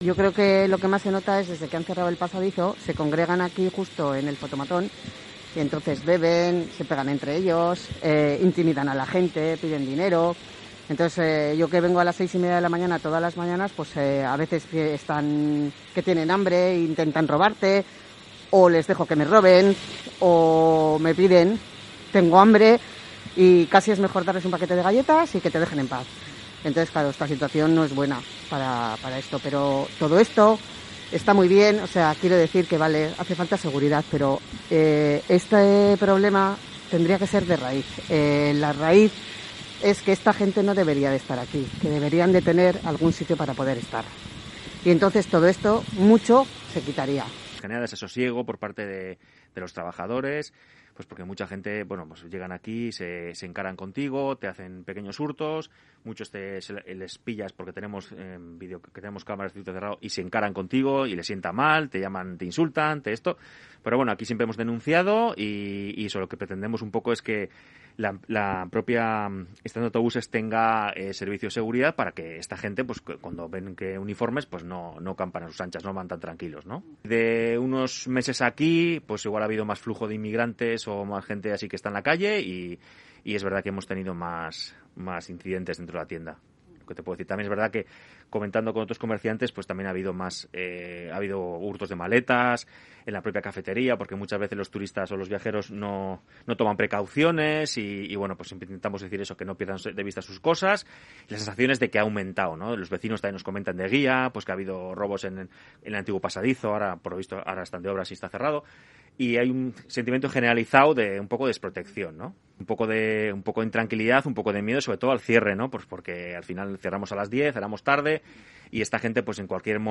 Puedes escuchar los testimonios recogidos por Onda Vasca en la estación de autobuses de Atotxa en el audio